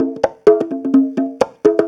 Conga Loop 128 BPM (22).wav